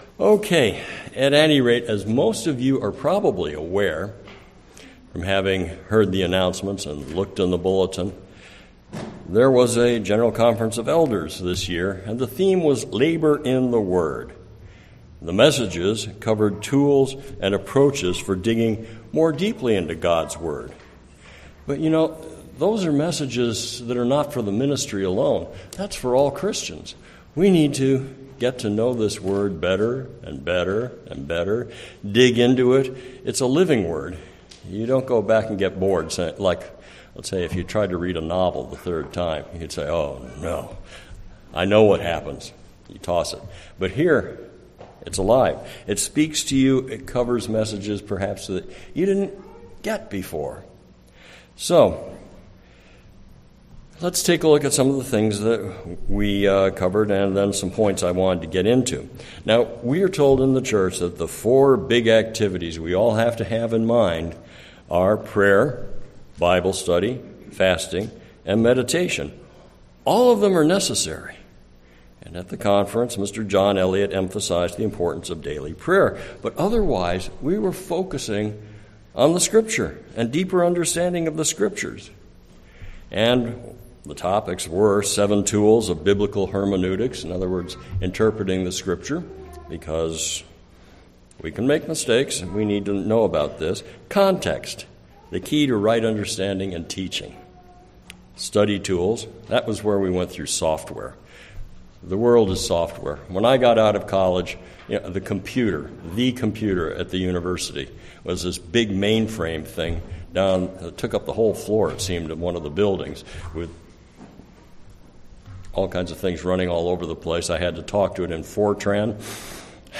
This sermon summarizes the various topics presented at the conference, and exhorts all of us to apply these principles to our personal Bible study.